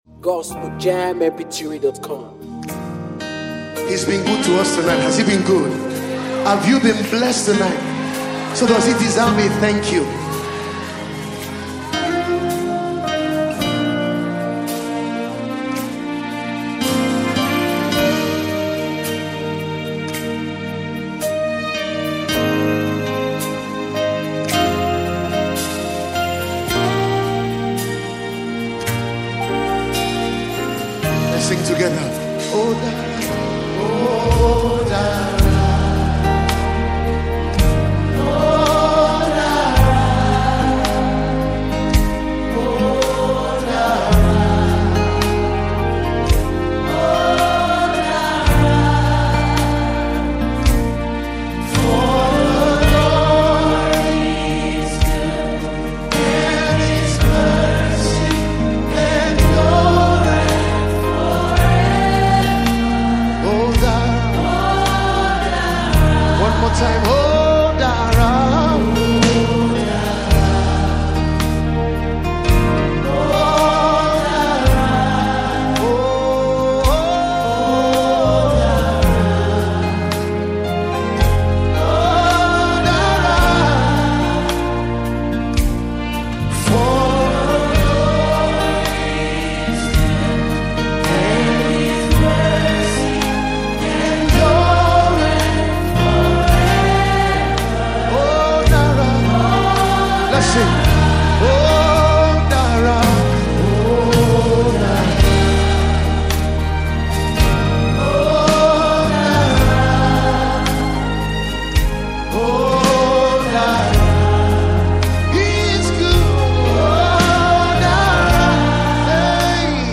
is a powerful song of praise